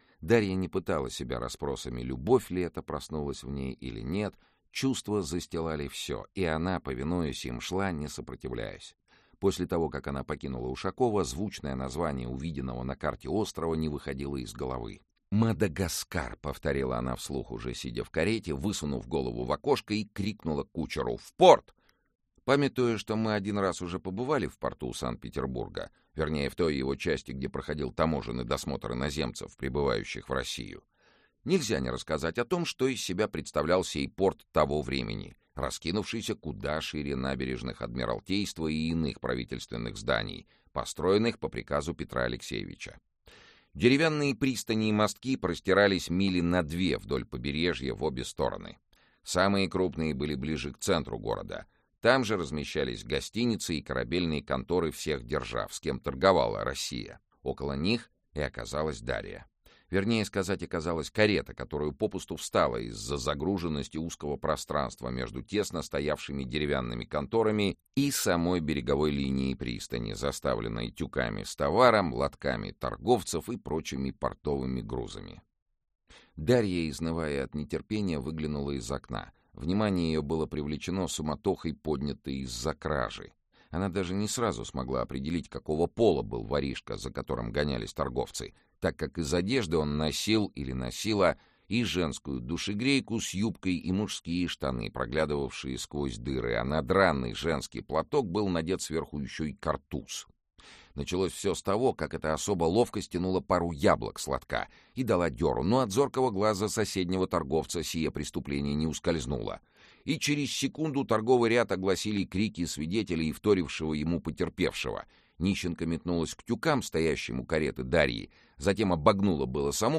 Aудиокнига Король Мадагаскара Автор Олег Рясков Читает аудиокнигу Сергей Чонишвили.